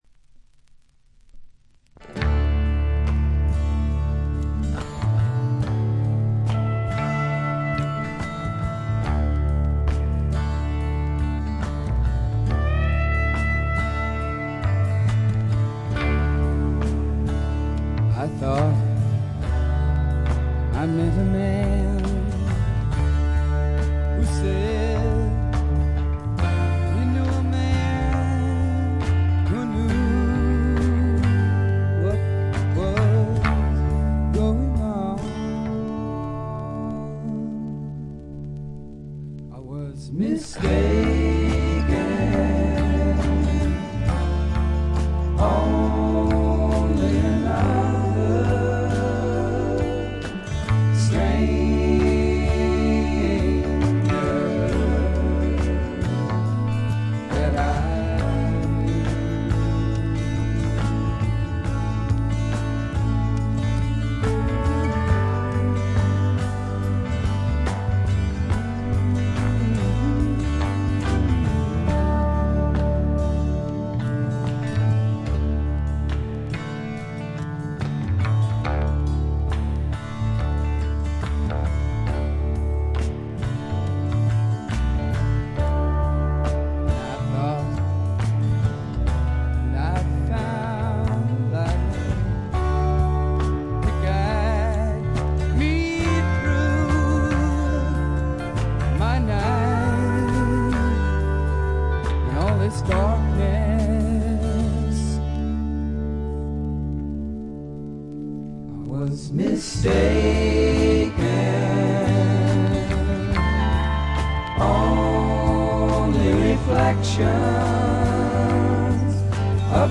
全体に細かなチリプチが出ていますが、特に目立つノイズはなくA-寄りの良品です。
試聴曲は現品からの取り込み音源です。